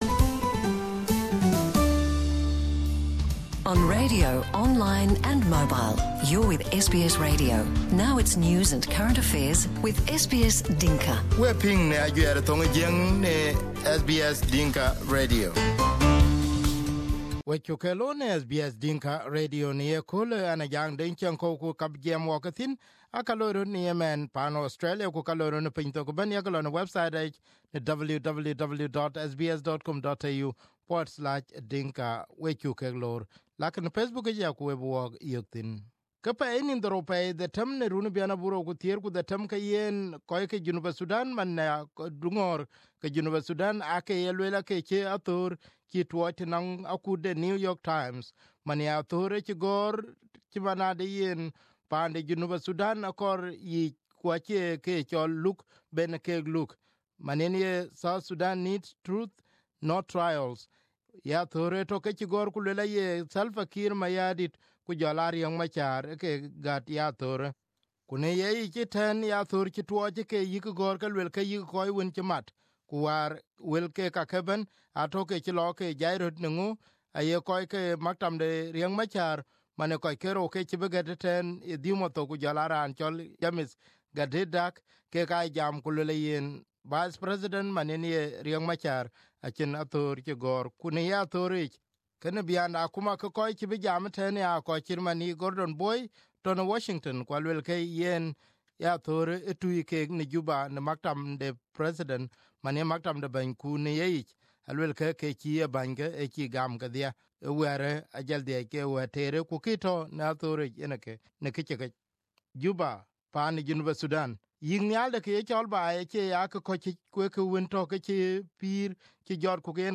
South Sudanese presidential spokesperson Ateny Wek Ateny confirmed to SBS Dinka Radio that the opinion piece published in the New York Times was from his office.